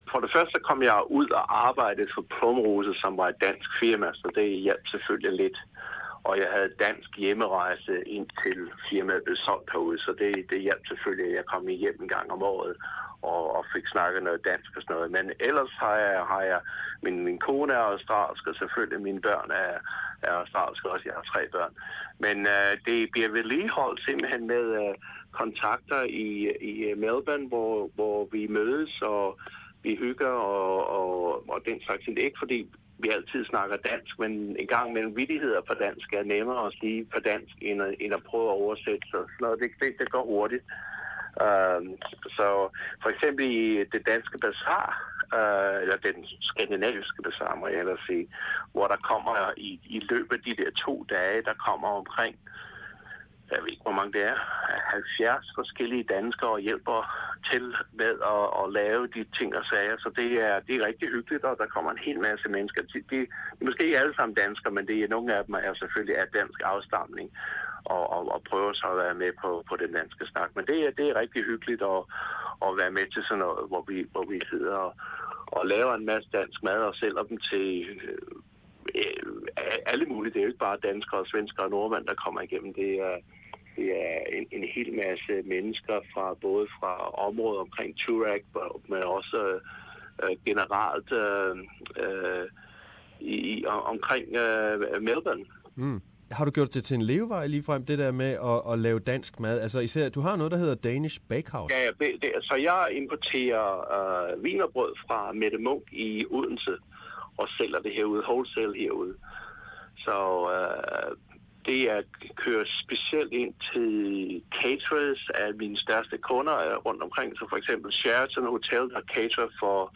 The interview is in Danish language.